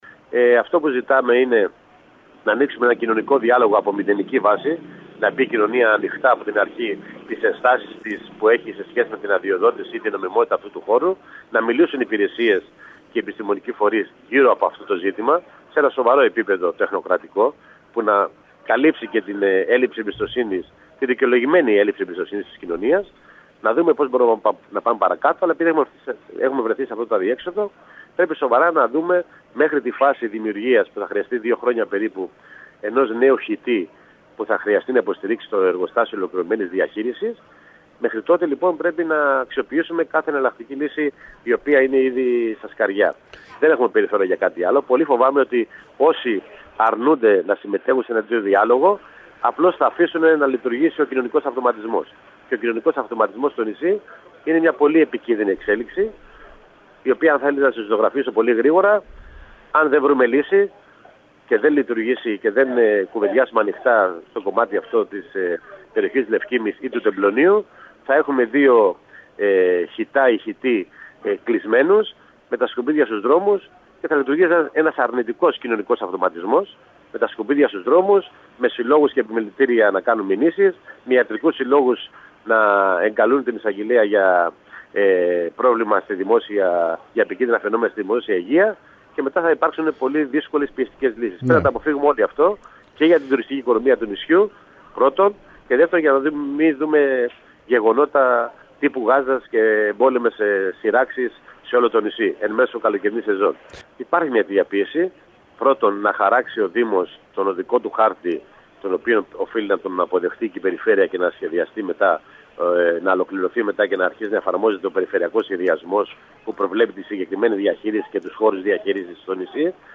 Την ανάγκη εκκίνησης διαλόγου από μηδενική βάση με το σύνολο των εμπλεκόμενων αυτοδιοικητικών και κοινωνικών φορέων στο θέμα των απορριμμάτων, τόνισε ο βουλευτής Κέρκυρας του ΣΥΡΙΖΑ Κώστας Παυλίδης μιλώντας στην ΕΡΤ Κέρκυρας.